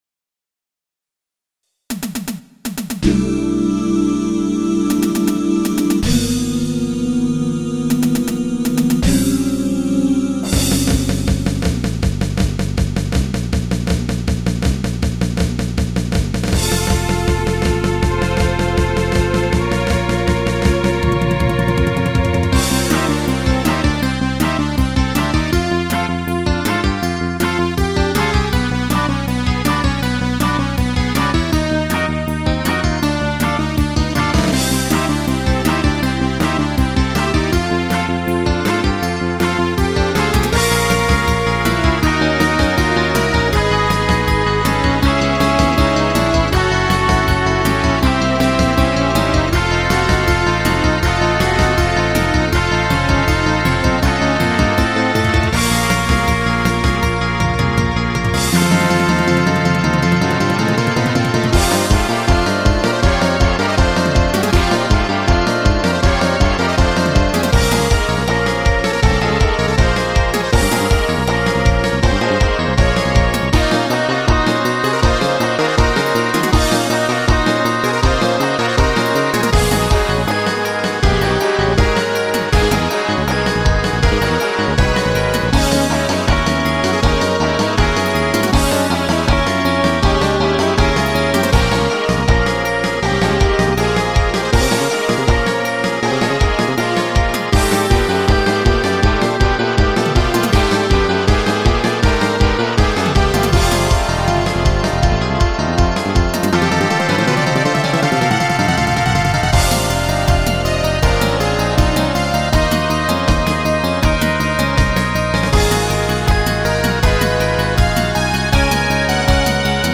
PROGRESSIVE ROCK MUSIC